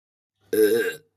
short ass burp - Botão de Efeito Sonoro